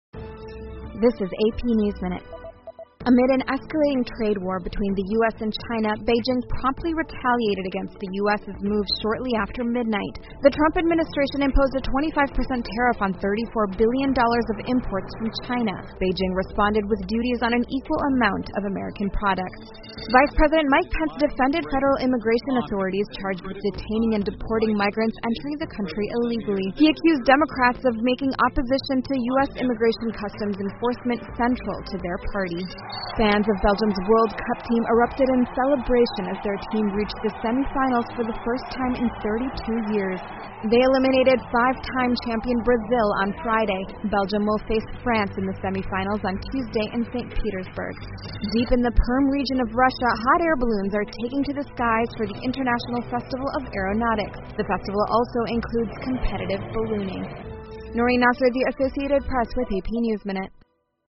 美联社新闻一分钟 AP 比利时打败巴西进半决赛 听力文件下载—在线英语听力室